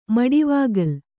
♪ maḍivāgal